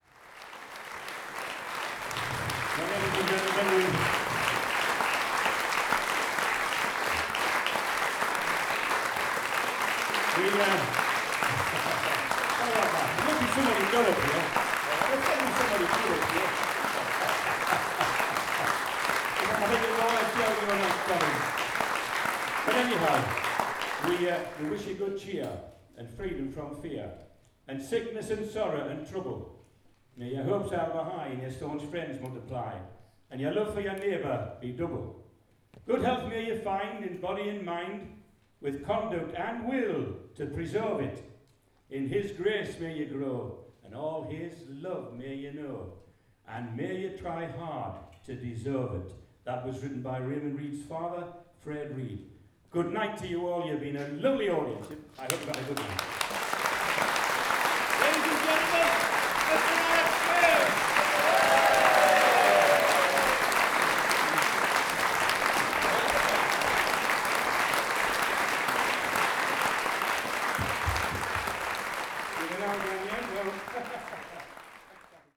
These recordings were made at the Northumbrian Night concerts which were part of the Alnwick International Music Festival in the years from 2009 to 2014. The 2010 concert was held in the Pavilion of the Alnwick Garden. All the rest were held in the Alnwick Playhouse.